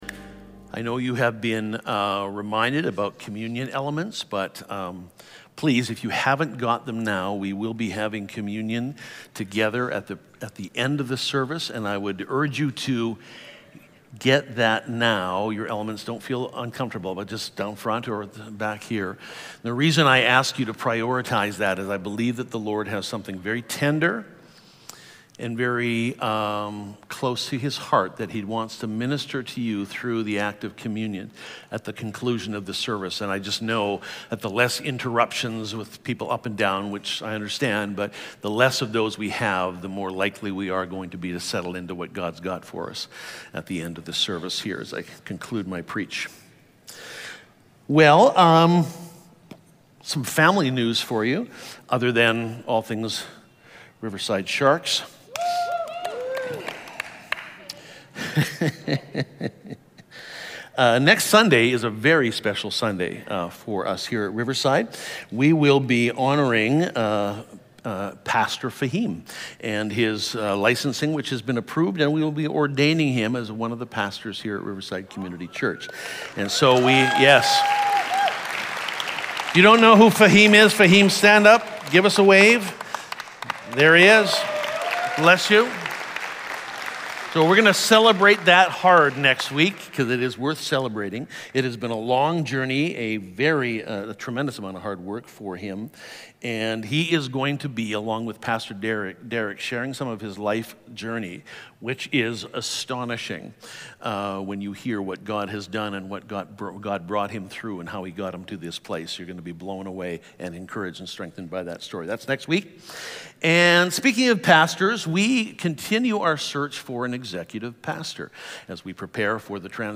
Sermons | Riverside Community Church